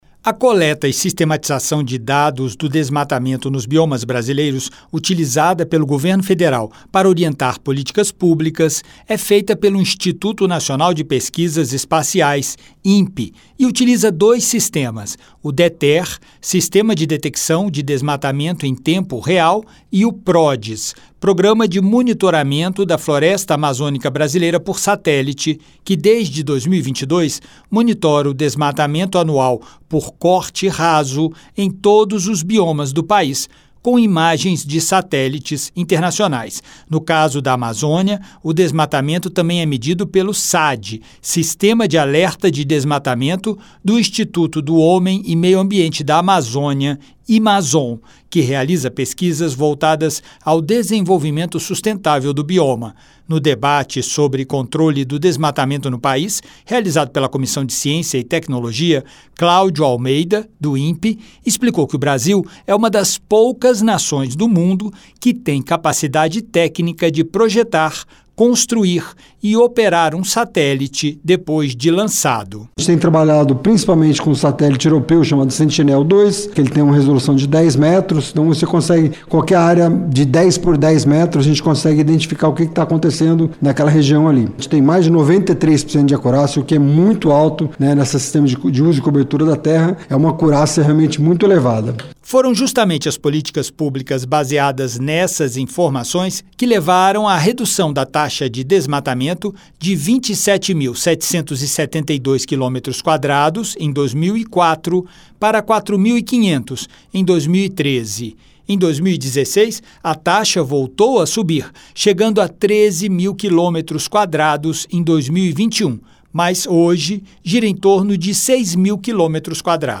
A Comissão de Ciência e Tecnologia (CCT) debateu na quarta-feira (6) o controle do desmatamento no Brasil (REQ 9/2025 - CCT).